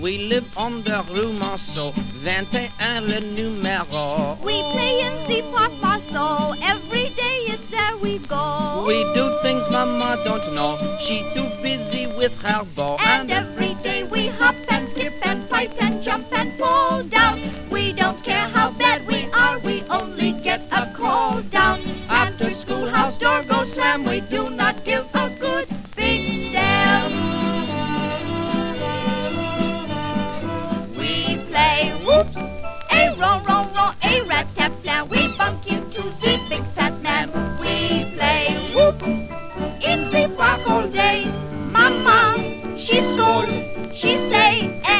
Jazz, Stage & Screen, Ragtime　USA　12inchレコード　33rpm　Mono